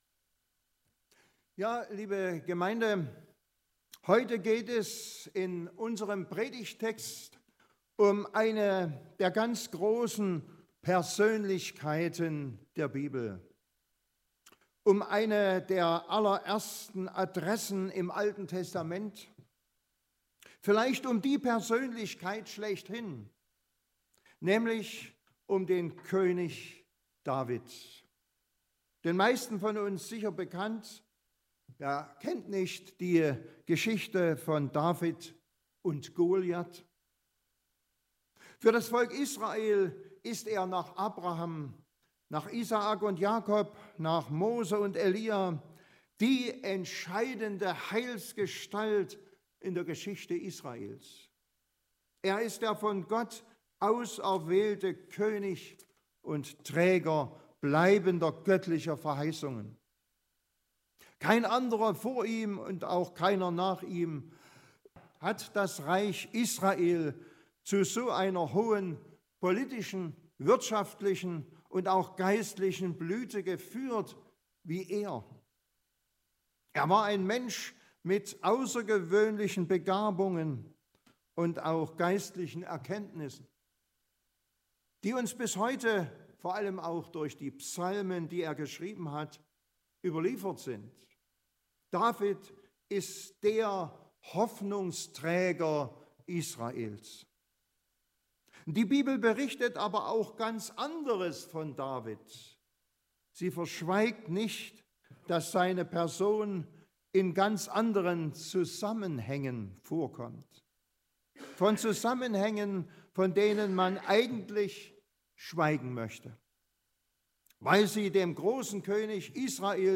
Samuel 12,1-10+13-15 Gottesdienstart: Predigtgottesdienst Obercrinitz König David war Vieles: eine schillernde Figur, der von Gott auserwählte König, aber auch Ehebreher und Mörder.